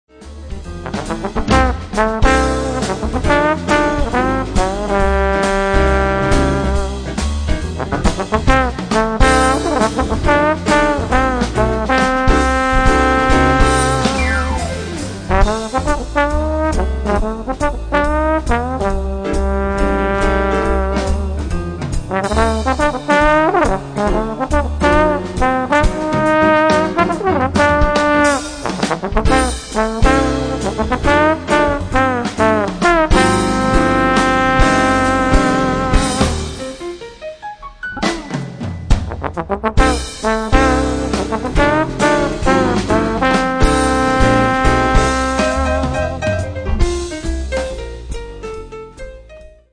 trombone
piano/organ
bass/bass guitar
drums
Recorded at NRK Studio 20, October 19th, 20th 1998.